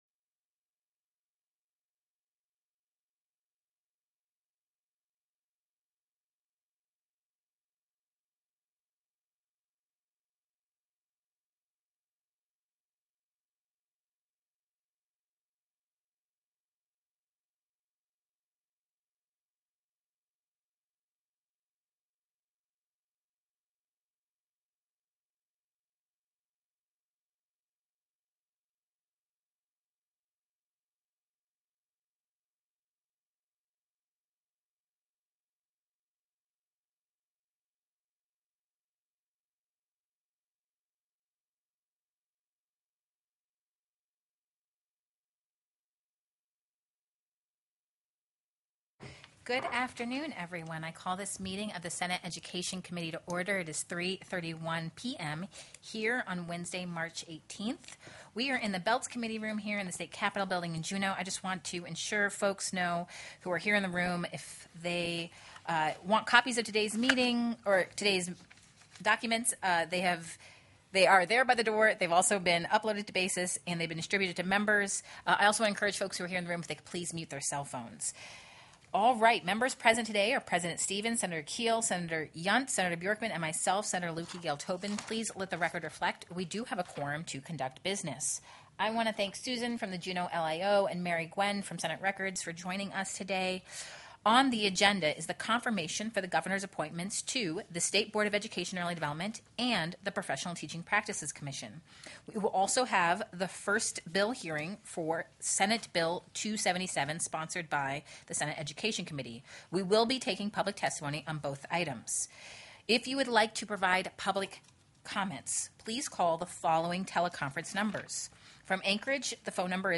The audio recordings are captured by our records offices as the official record of the meeting and will have more accurate timestamps.
Confirmation Hearing(s):
TELECONFERENCED
-- Invited & Public Testimony --